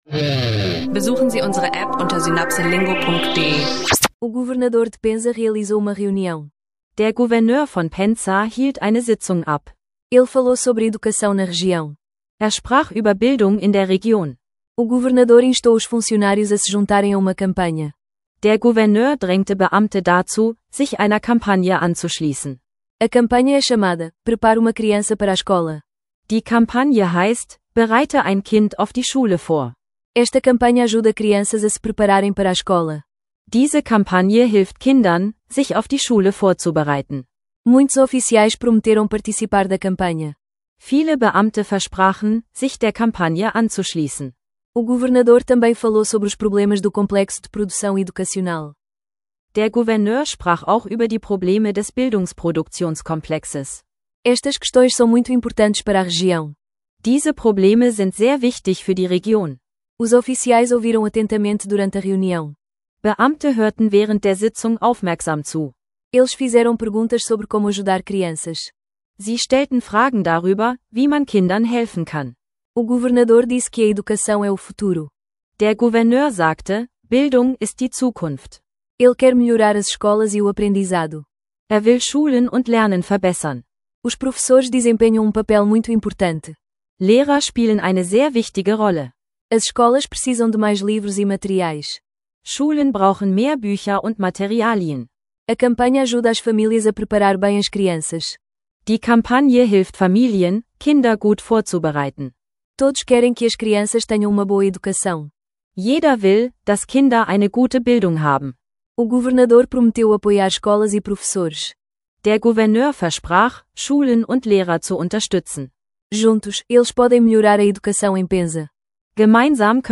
Lerne Portugiesisch im Alltag durch unseren Podcast-Stack: Bildungsinitiativen in Penza, Filme, Arztbesuche und mehr. Perfekt für Anfänger und Fortgeschrittene, mit kurzen Dialogen, Vokabeln und nützlichen Phrasen.